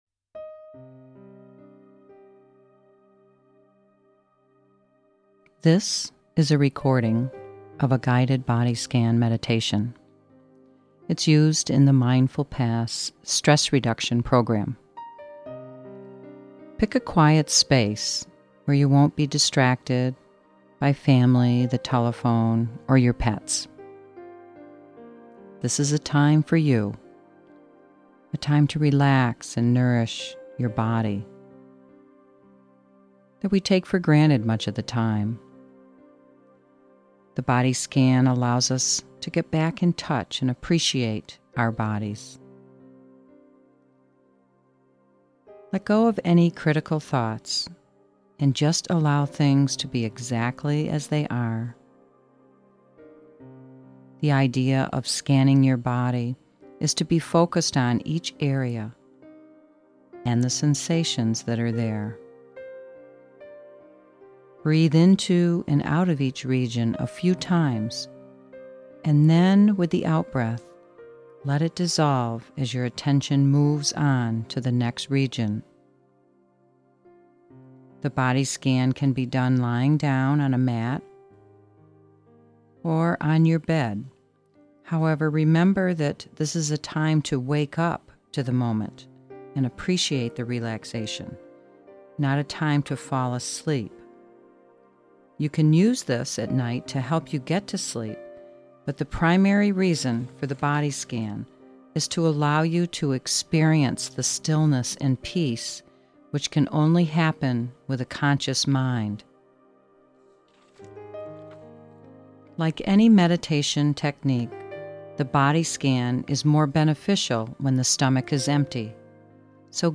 Guided Body Scan Meditation and Stress Reduction
Guided Body Scan for web.mp3